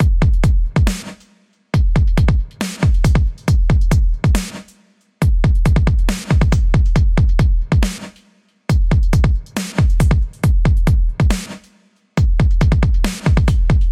网络配音节拍与帽子 138BPM
描述：同样的dubstep节拍，带着帽子，速度为138BPM
Tag: 138 bpm Dubstep Loops Drum Loops 2.34 MB wav Key : Unknown